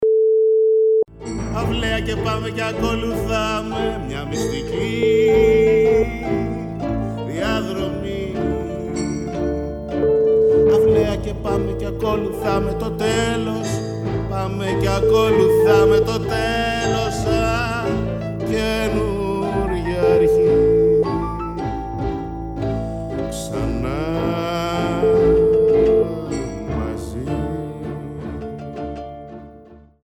Ορχηστρικό